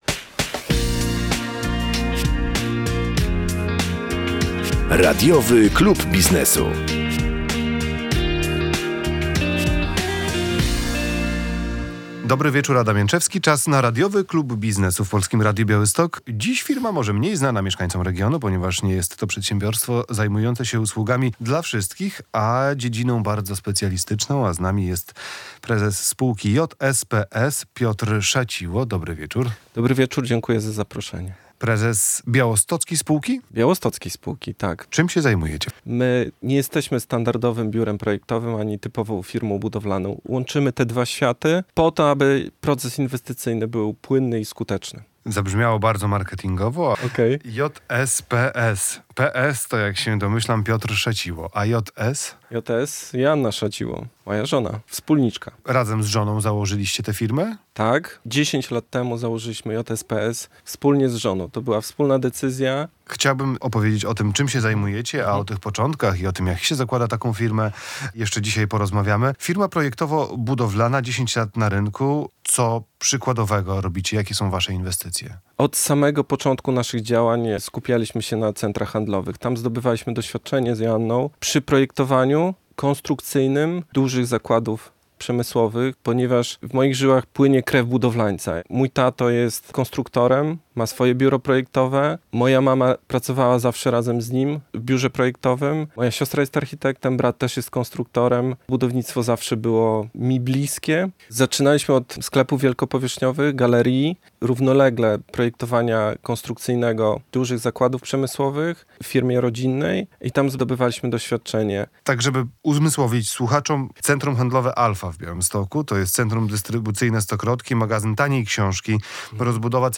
Dlaczego spółka JSPS nie jest standardowym biurem projektowym ani typową firmą budowlaną? Rozmowa